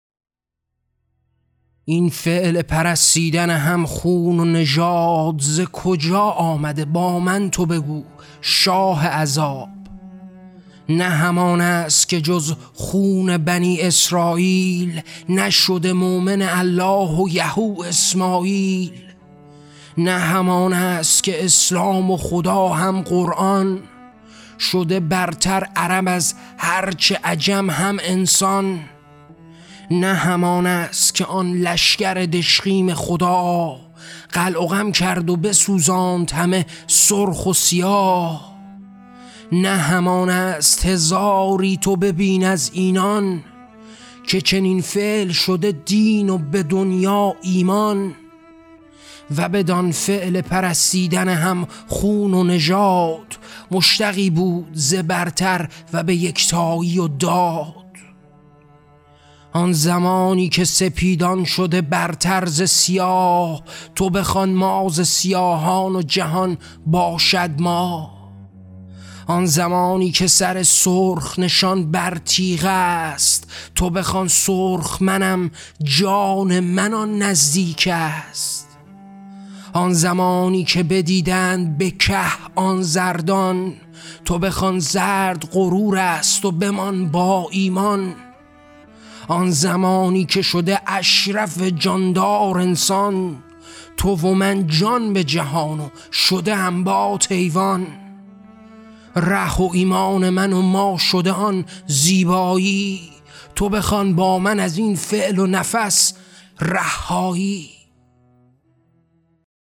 موسیقی :